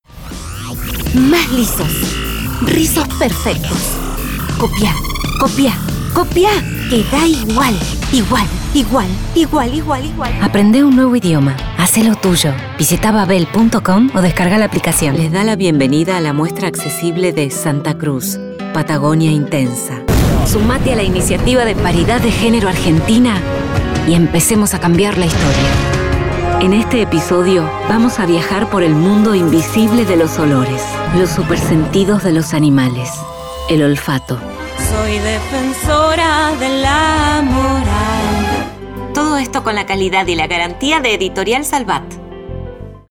成熟女声